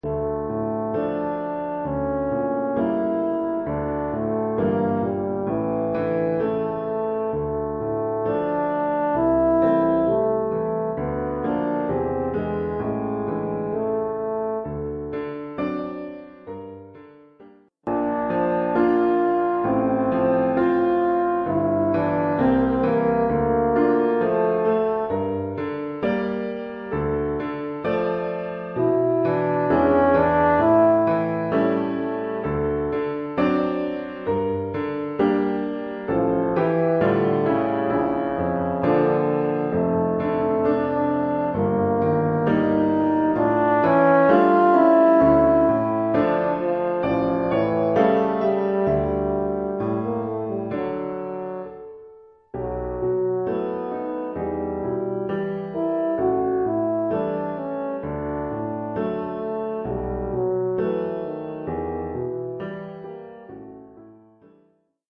Oeuvre pour cor d'harmonie et piano.
Oeuvre pour cor d'harmonie
(fa ou mib) et piano.